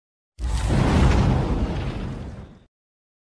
Видеопереход ОГОНЬ для видеомонтажа